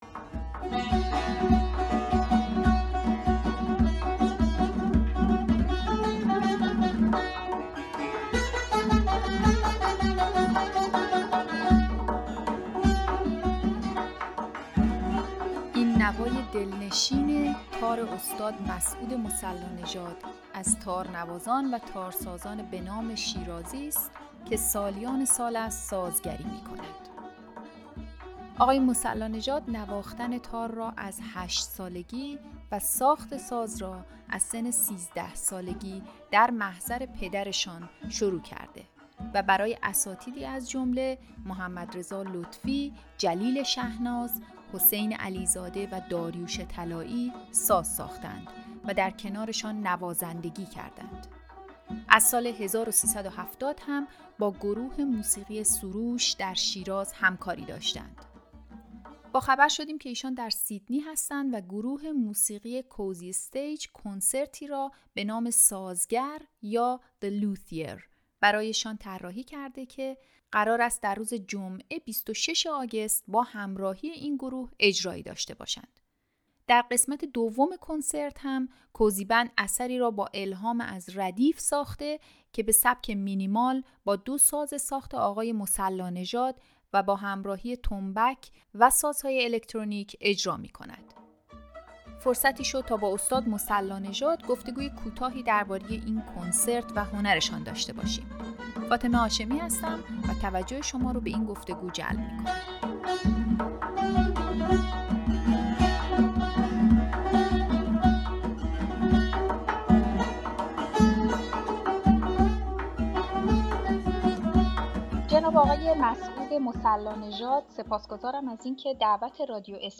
گفتگوی کوتاهی درباره این کنسرت و هنرشان